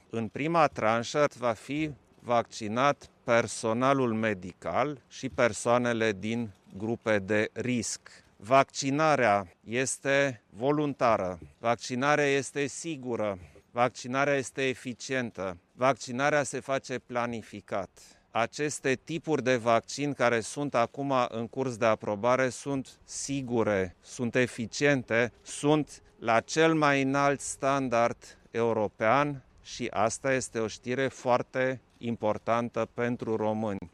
Strategia de vaccinare a fost aprobată, ieri, în Consiliul Suprem de Apărare a Ţării, a anunţat preşedintele Klaus Iohannis, la finalul unei vizite la un centru de vaccinare din Bucureşti.
Şeful statului a precizat şi ce categorii vor avea prioritate la imunizare: